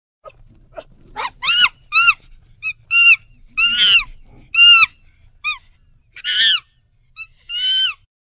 Звук - Шимпанзе (Сhimpanzee)
Отличного качества, без посторонних шумов.